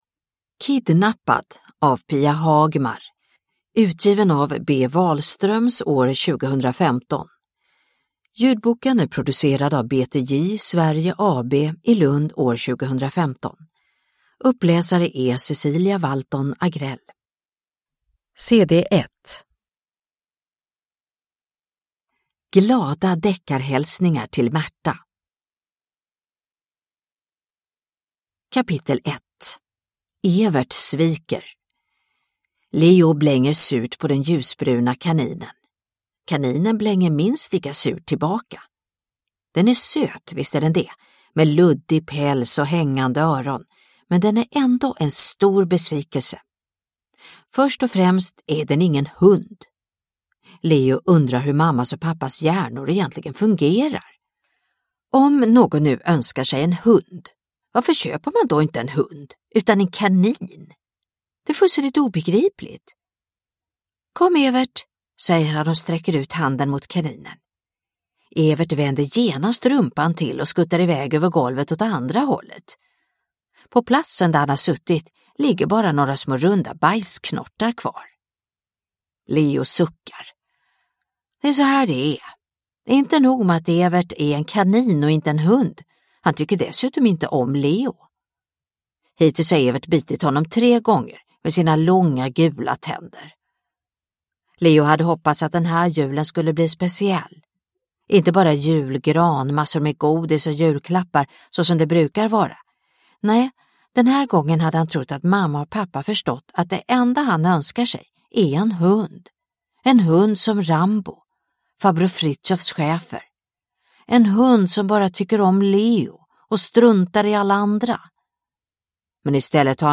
Kidnappad – Ljudbok – Laddas ner